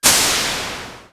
GasReleasing09.wav